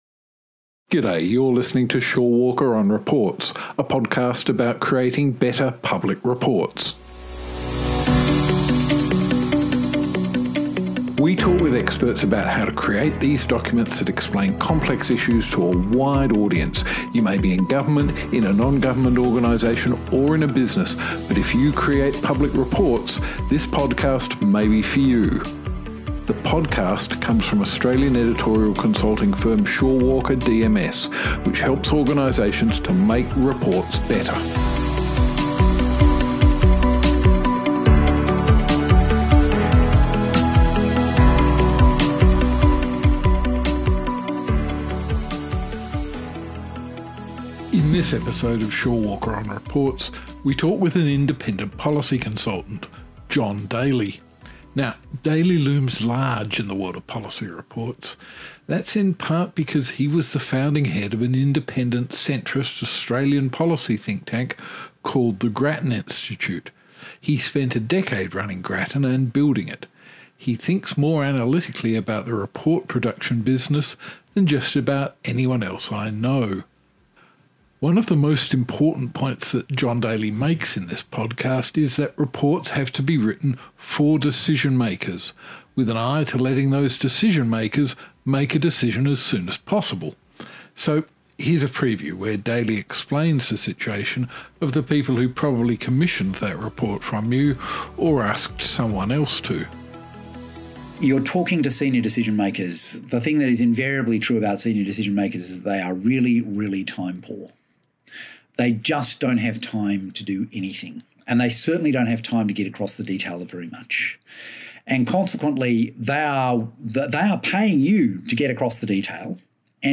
In this episode of Shorewalker on Reports , we talk with an independent policy consultant